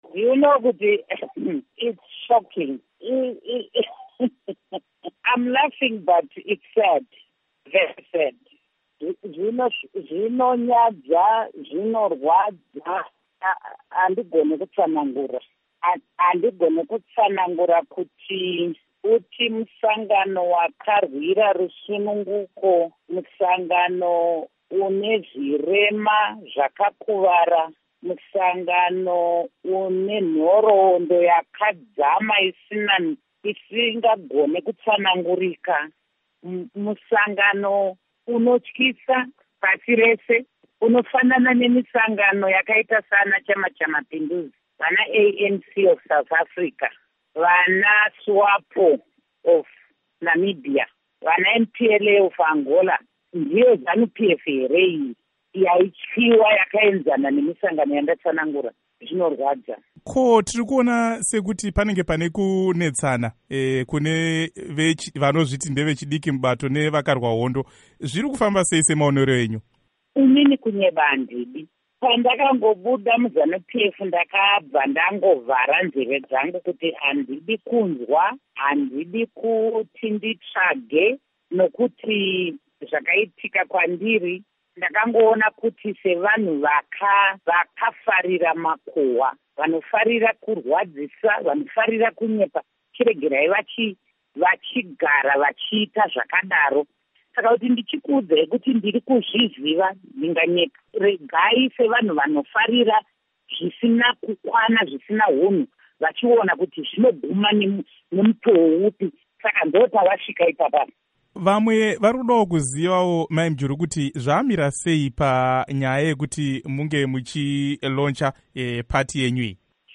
Amai Mujuru vabvunzwa neStudio 7 kuti ichokwadi here kuti vari kufanotungamira bato reZimbabwe People First vakapindura kuti, “Hongu.”
Hurukuro naAmai Joice Mujuru